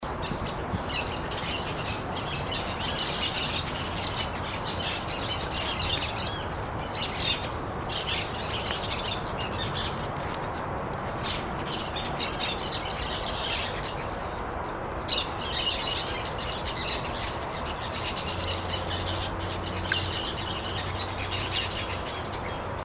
Monk Parakeet (Myiopsitta monachus)
Varios individuos posados en un Palo Borracho rosa florecido.
Location or protected area: Parque 3 de Febrero
Condition: Wild
Certainty: Recorded vocal